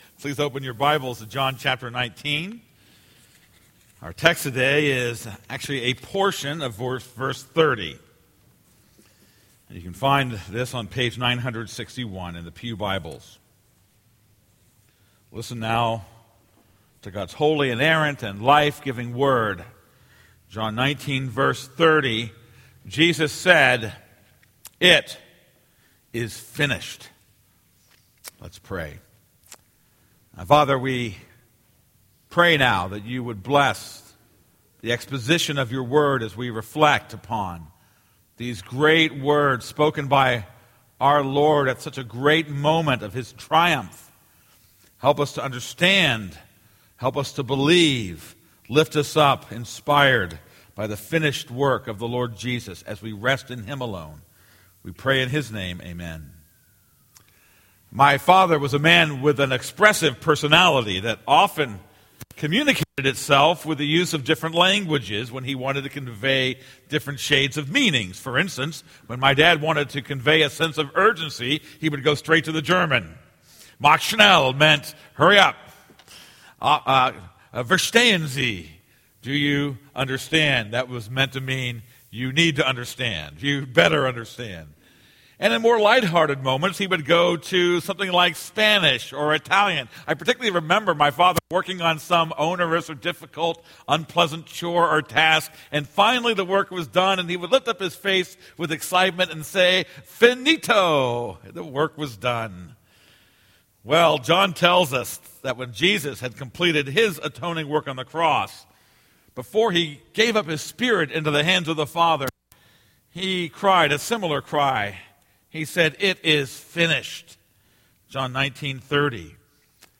This is a sermon on John 19:30.